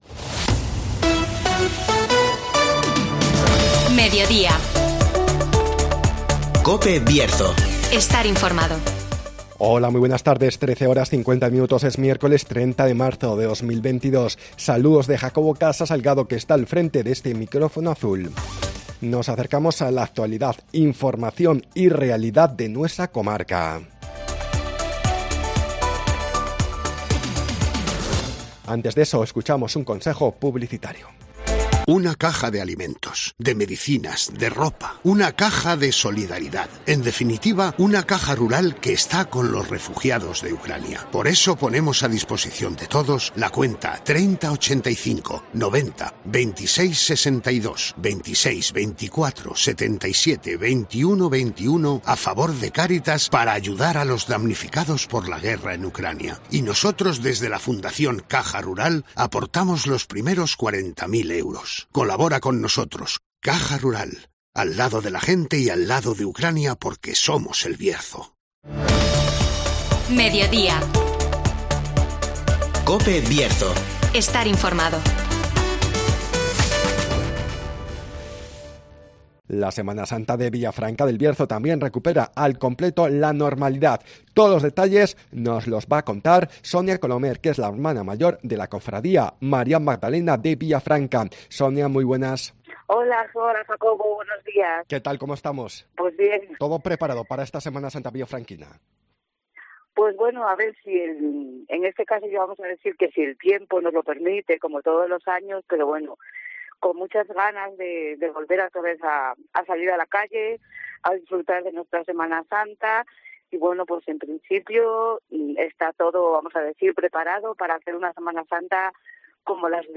La Semana Santa de Villafranca del Bierzo también recupera al completo la normalidad (Entrevista